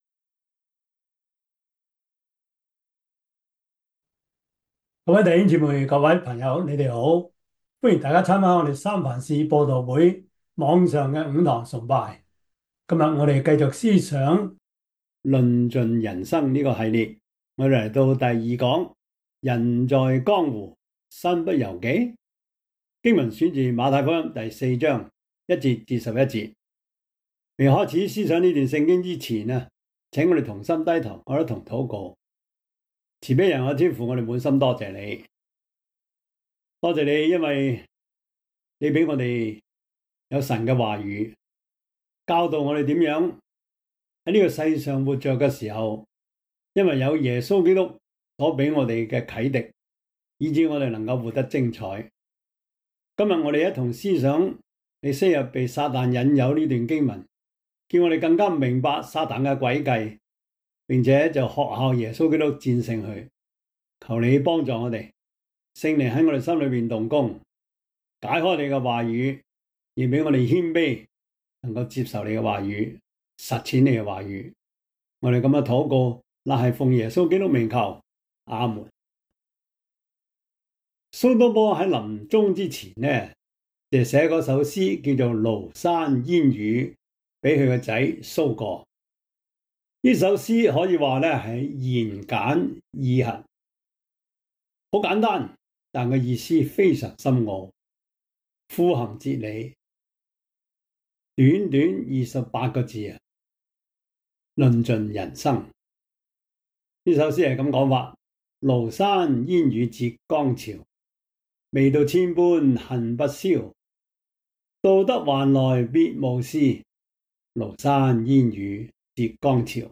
傳道書 3:1-15 Service Type: 主日崇拜 馬太福音 4:1-11 Chinese Union Version